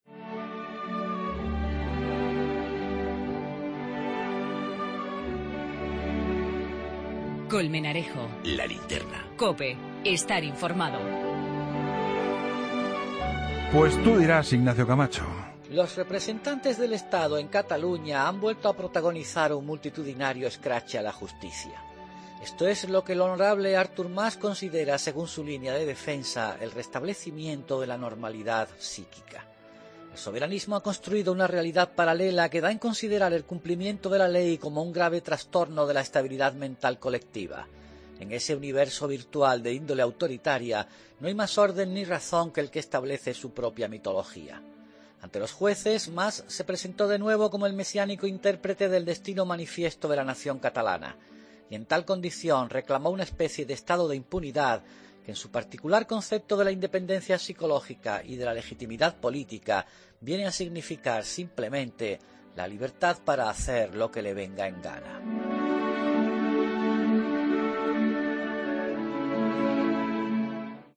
El comentario de Ignacio Camacho en 'La Linterna' sobre el juicio a Artur Mas por la consulta del 9-N